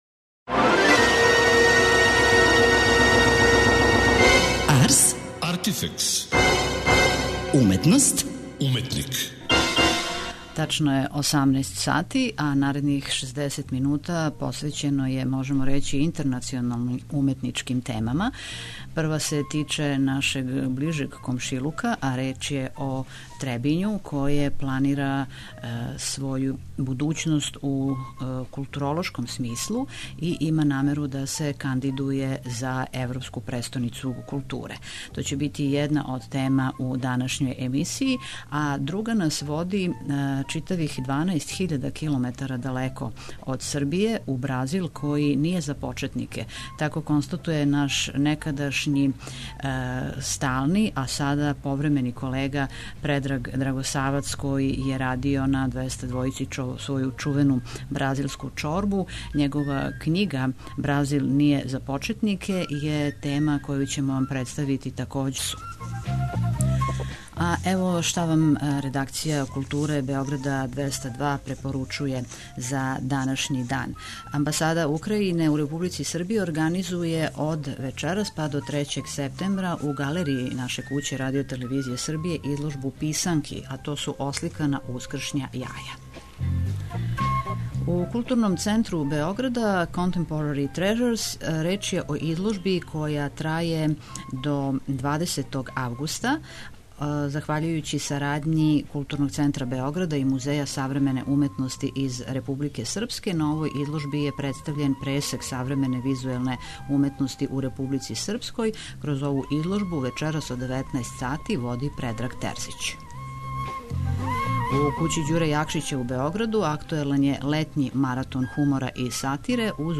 а разговор можете чути у данашњој емисији.